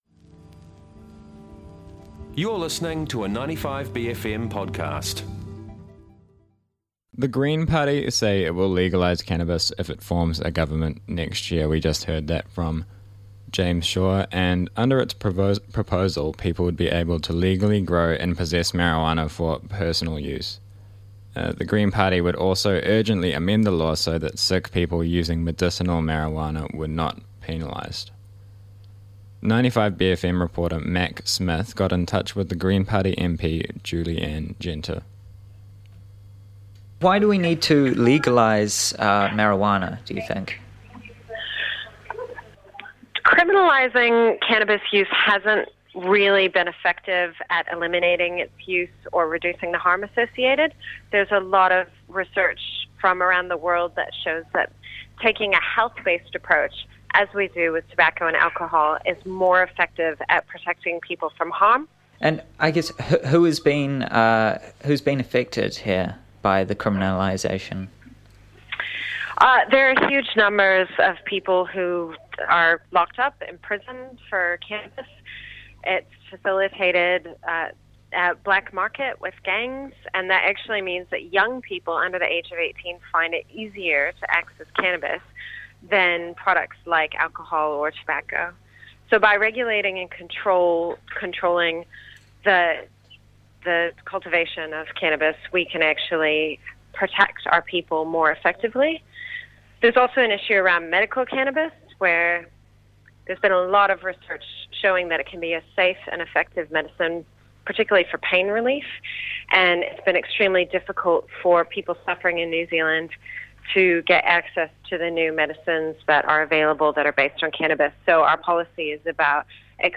speaks to Green MP Julie Anne Genter and media commentator